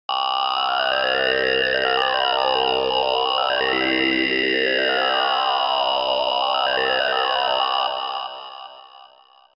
ヘッドホンで聞くとよくわかります。
左右に揺れる歌声（MP3）